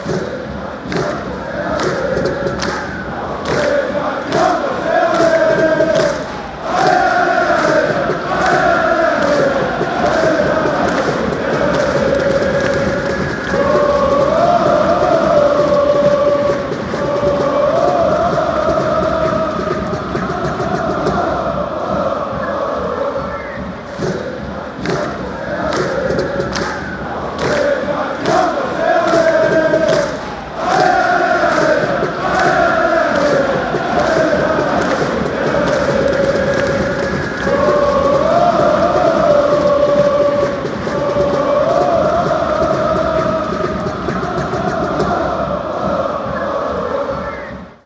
Die beiden Stadionhälften rufen abwechselnd "Hopp YB".Wenn der Grasshopper Club Zürich zu Gast ist, kann es passieren, dass die einte Hälfte "Sche*ss GC" ruft.